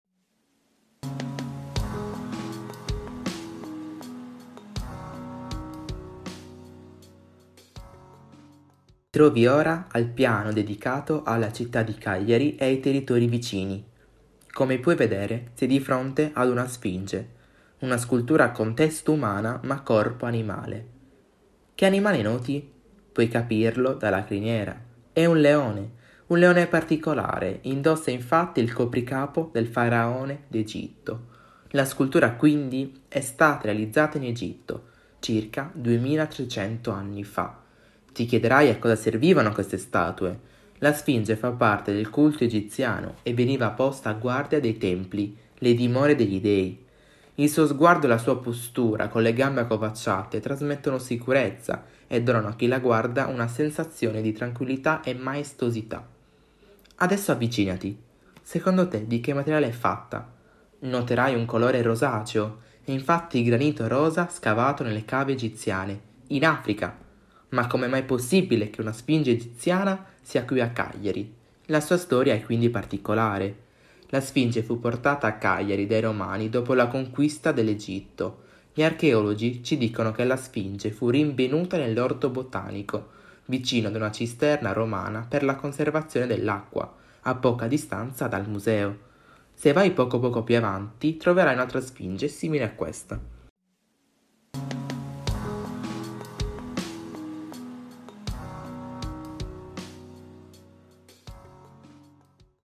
(Italiano) audioguida - Sfinge